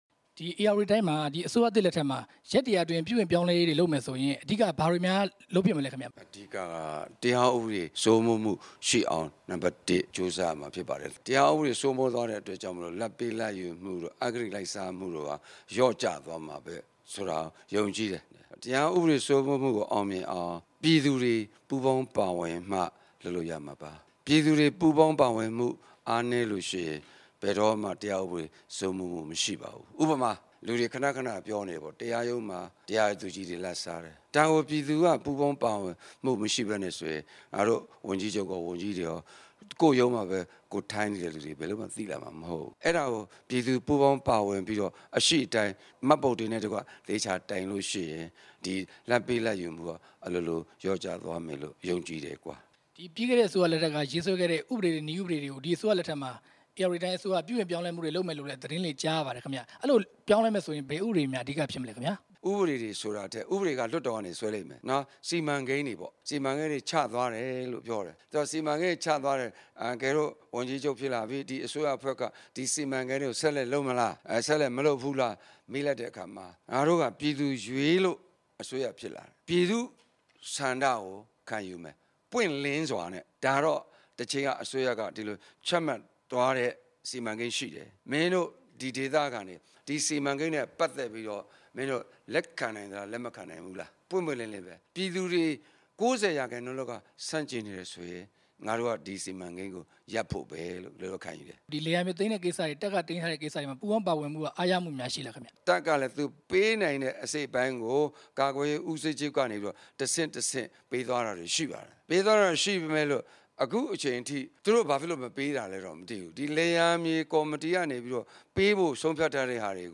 ၀န်ကြီးချုပ် မန်းဂျော်နီ ကို မေးမြန်းချက်